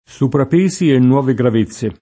soprapeso [Soprap%So] s. m. (ant. «aggravio ulteriore»); pl. -si — es.: soprapesi e nuove gravezze [
Soprap%Si e nnU0ve grav%ZZe] (Bembo) — meno raro nelle locuz. per s., di s. — diverso da sovrappeso